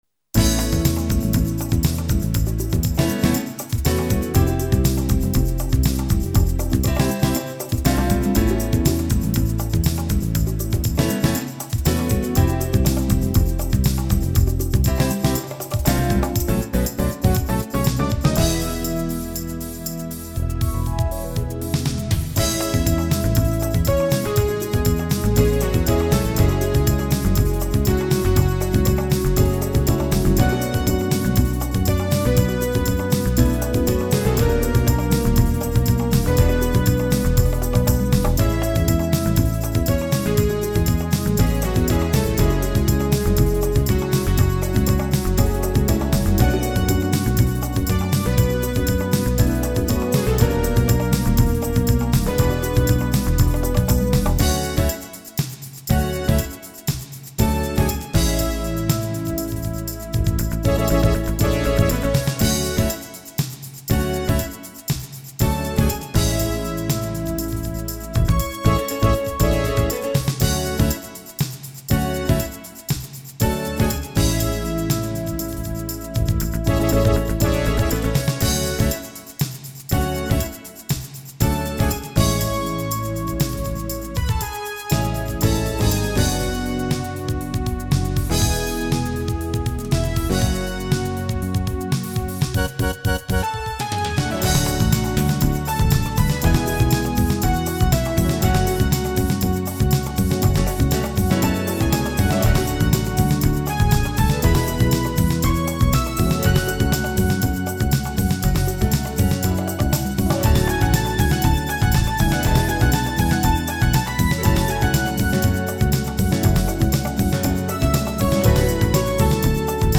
Really cool, almost arrange sounding Remix